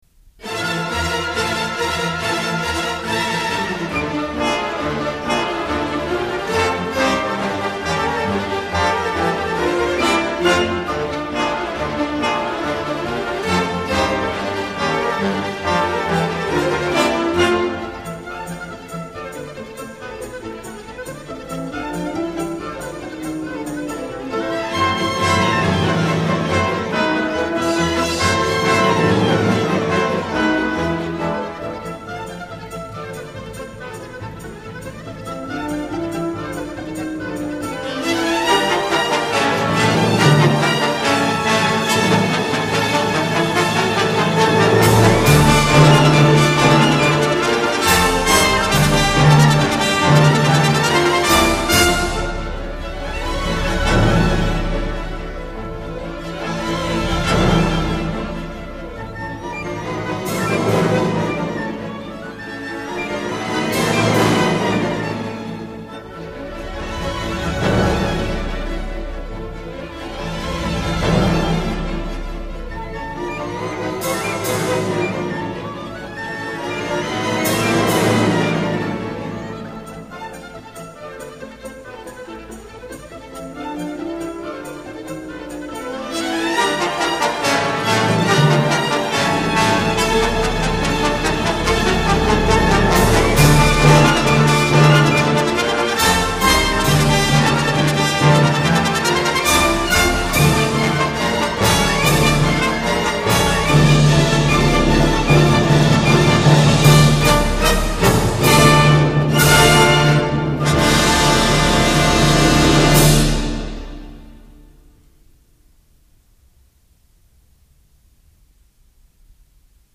类别：管弦乐
这是一张老少咸宜，可听性强又十脚火爆的通俗古典音乐。